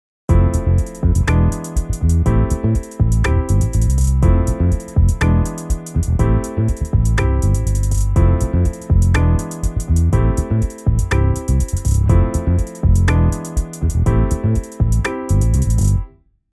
今回はイメージに最も近い「Modern R&B」を選びました。
▶ベースのフレーズ調整後
Logic-11-Bass.mp3